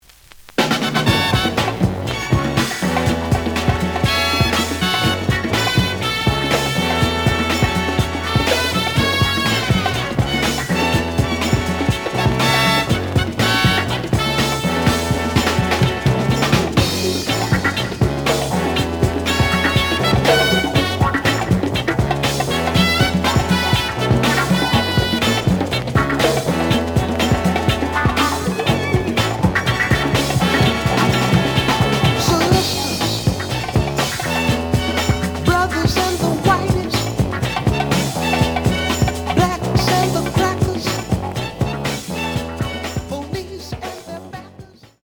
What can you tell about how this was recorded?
The audio sample is recorded from the actual item. Edge warp.